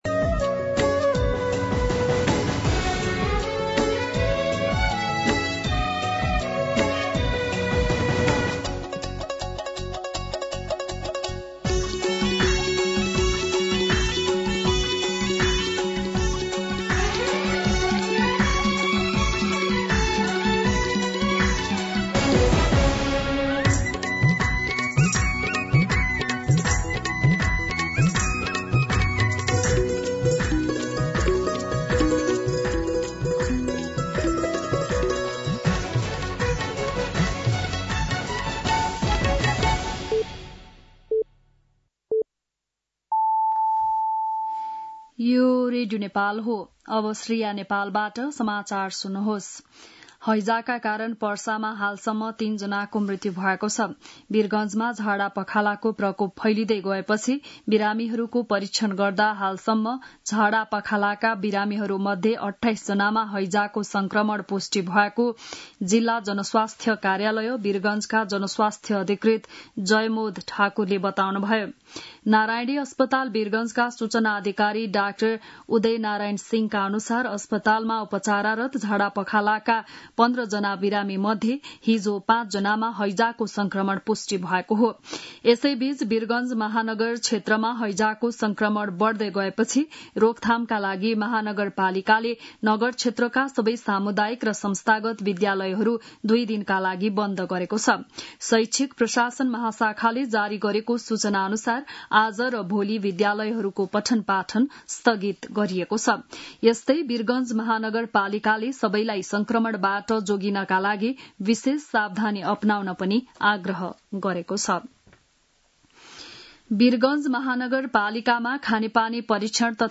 An online outlet of Nepal's national radio broadcaster
बिहान ११ बजेको नेपाली समाचार : १८ पुष , २०२६
11am-News-08.mp3